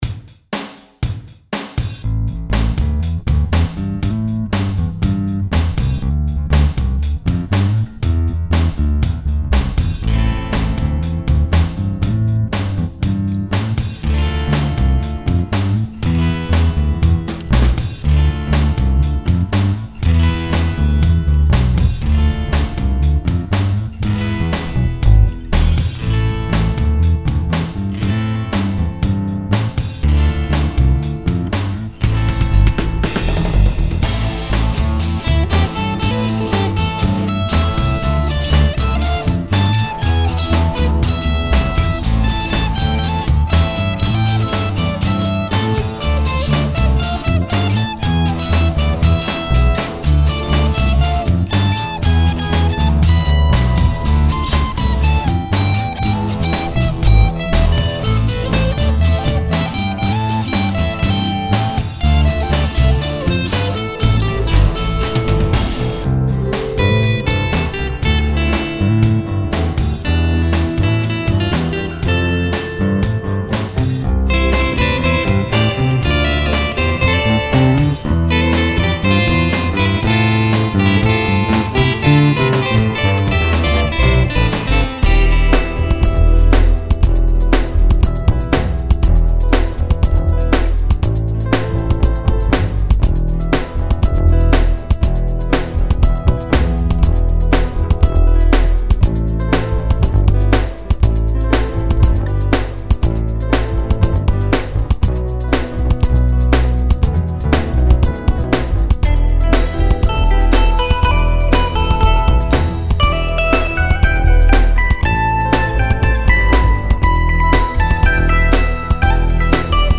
That's the case here: the song, such as it is, really amounts to an excuse to fool around with a bass....
BassStroll.mp3